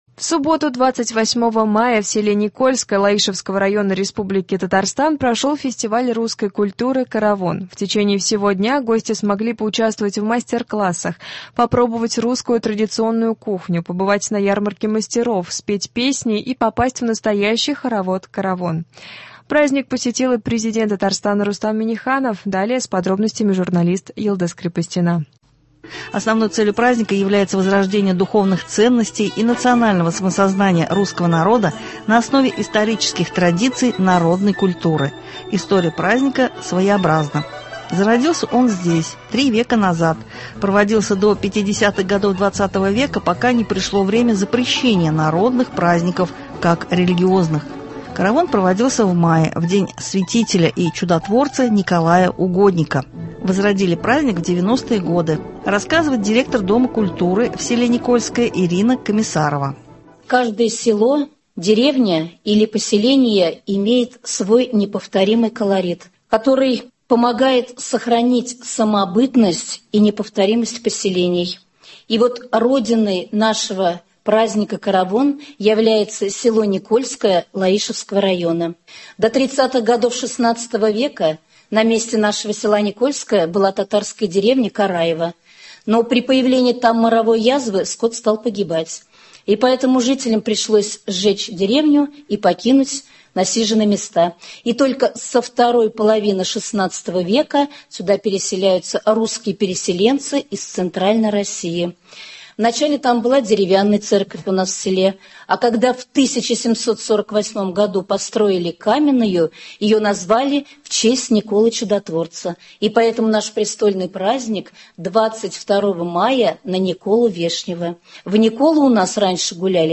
Репортаж с праздника русской культуры «Каравон».